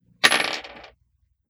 Bouncing Bullet 004.wav